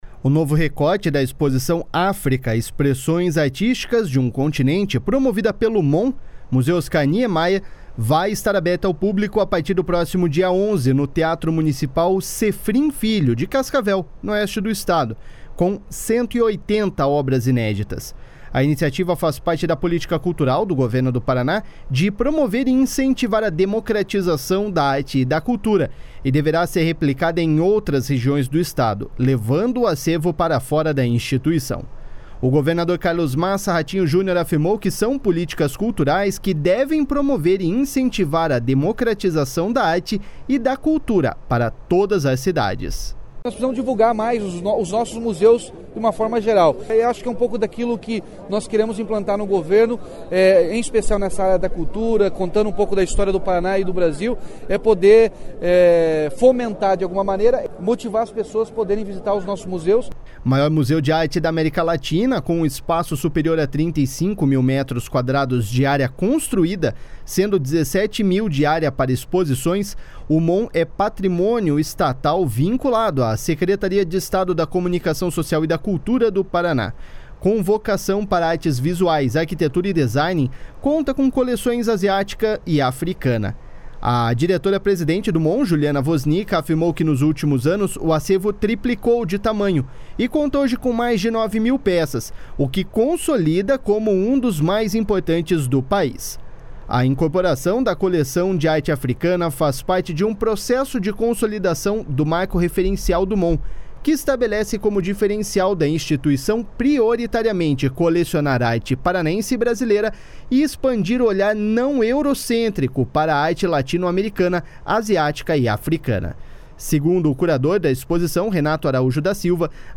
//SONORA RATINHO JUNIOR//